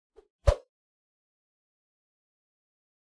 meleeattack-swoosh-light-group05-00.ogg